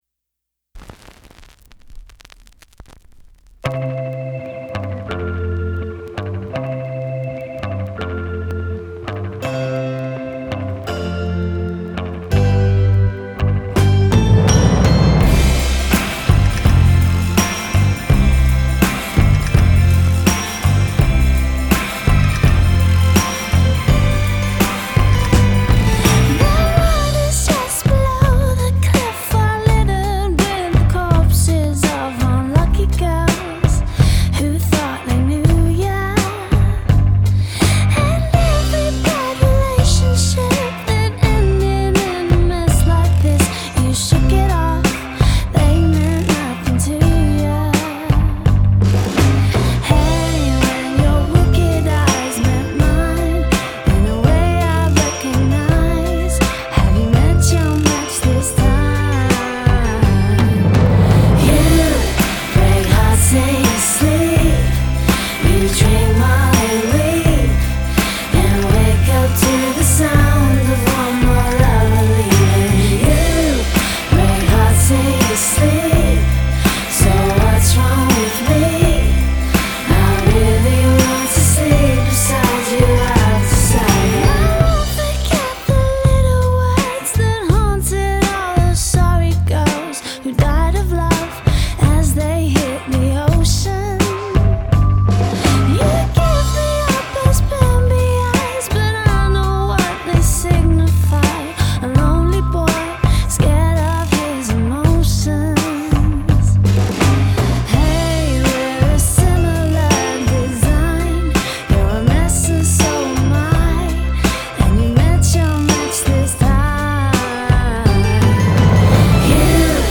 retro girl pop
a real hook of a chorus